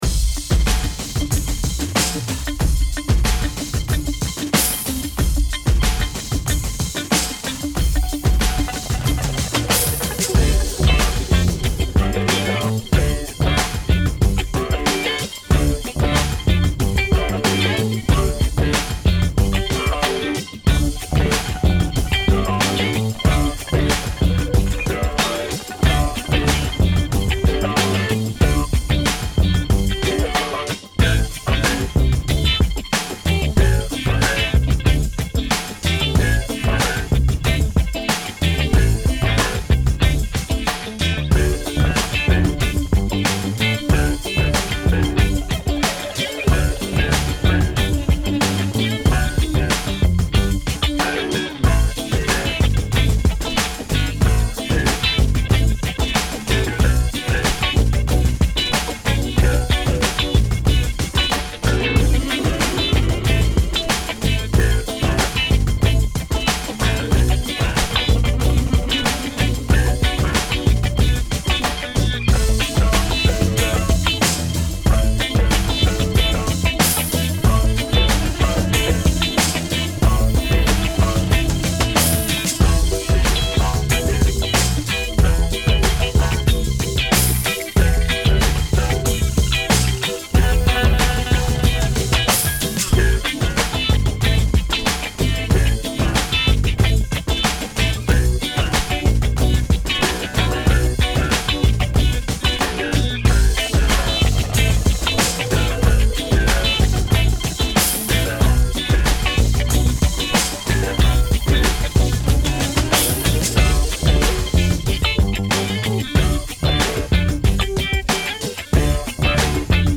Worked up this track with a little funk.
bass
guitar